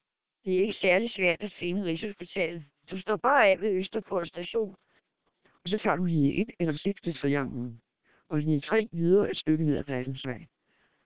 Also, all independent listeners preferred SPR, noting its significantly higher speech quality and intelligibility.
spr_1200_da_short.wav